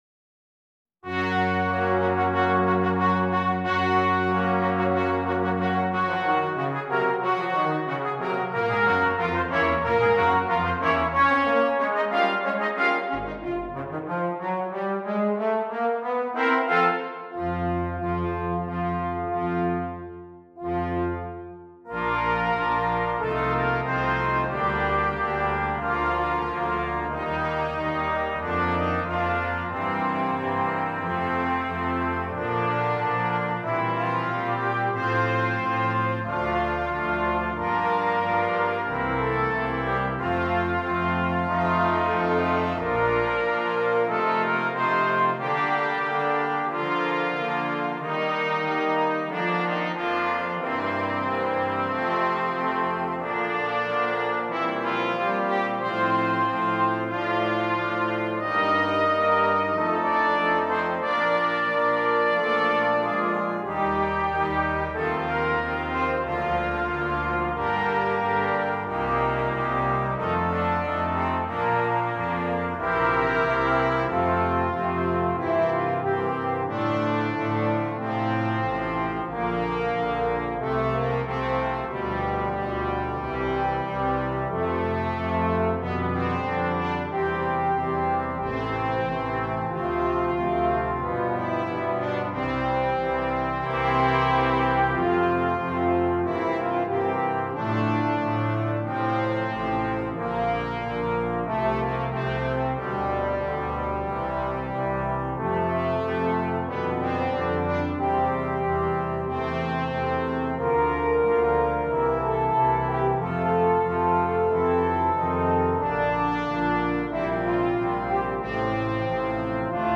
Brass Choir (4.2.2.1.1)